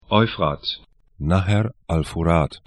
Pronunciation
Euphrat 'ɔyfrat Nahr al Furāt 'nahɛr al fu'ra:t ar Fluss / stream 34°25'N, 41°00'E